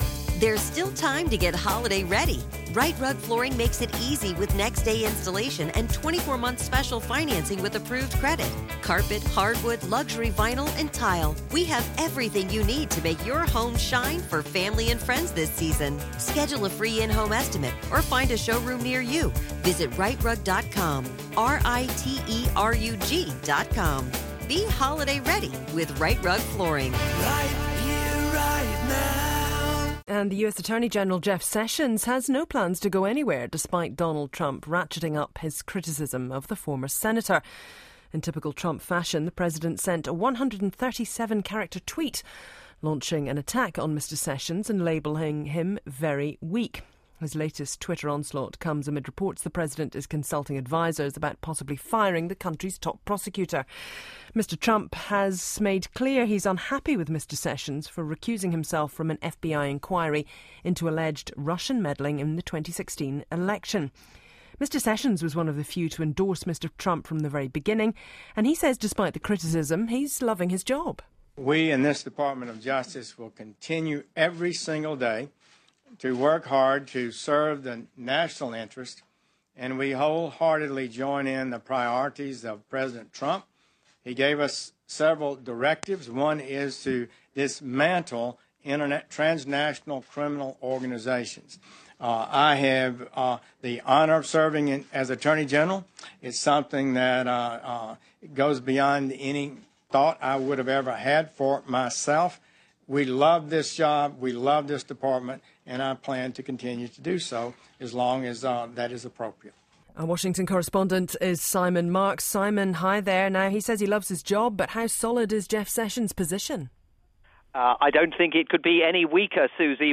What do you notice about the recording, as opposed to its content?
report on Jeff Session's wafer-thin hold over the job of US Attorney General, and the extraordinary disloyalty shown to him by President Trump. Via Radio New Zealand's "Morning Report"